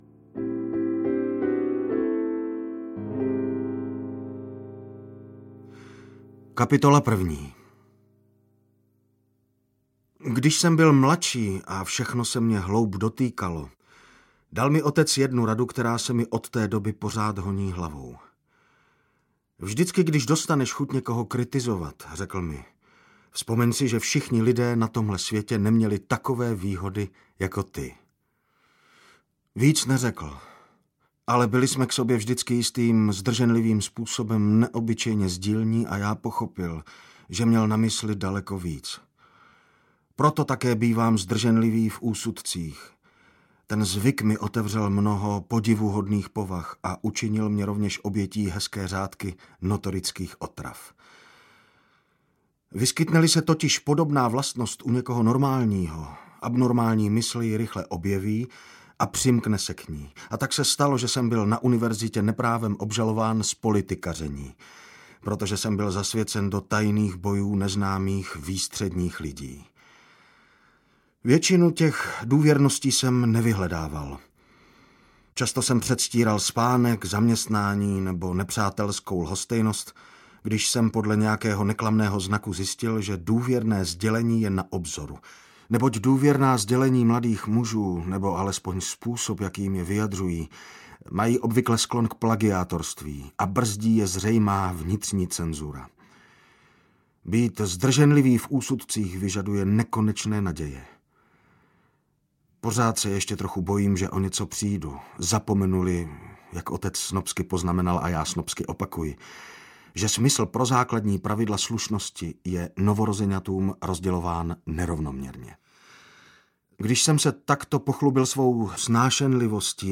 Ukázka z knihy
velky-gatsby-audiokniha